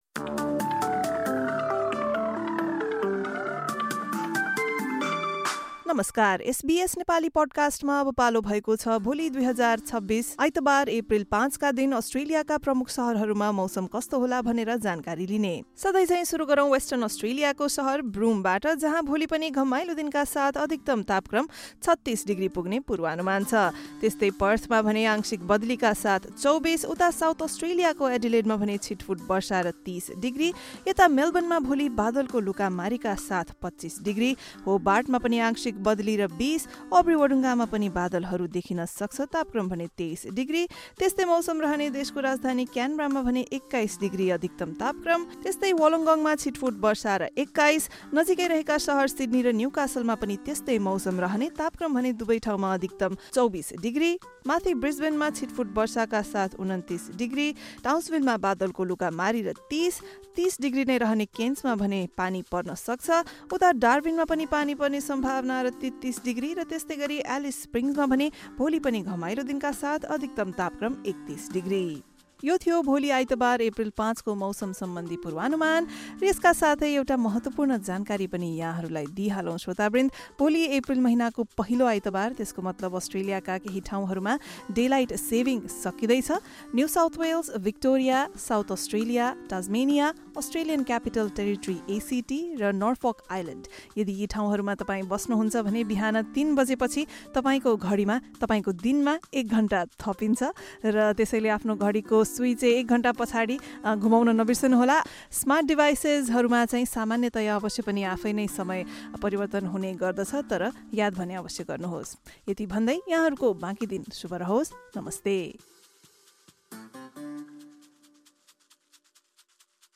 Weather update for major cities across Australia in Nepali. This update features tomorrow’s forecast for the following cities: Adelaide, Melbourne, Hobart, Albury-Wodonga, Sydney, Newcastle, Darwin, and Alice Springs.